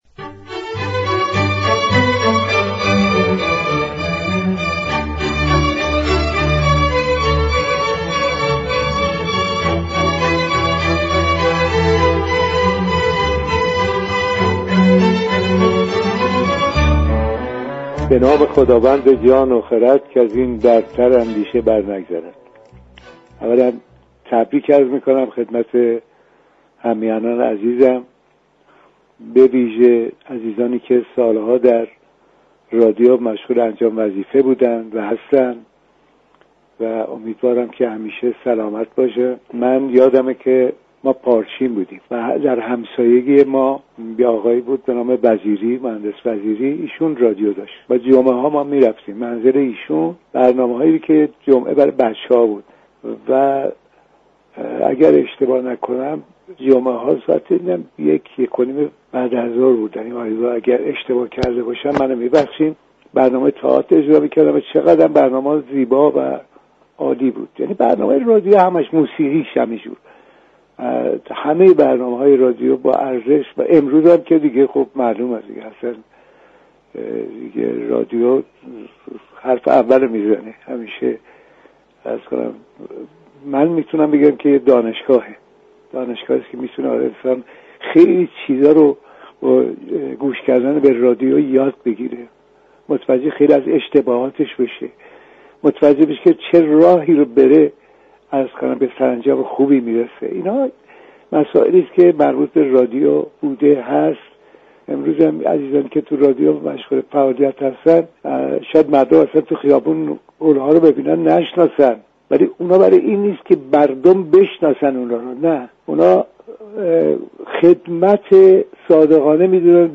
جمشید مشایخی بازیگر پیشكسوت تئاتر، سینما و تلویزیون در گفت و گو با برنامه «خانه و خانواده» گفت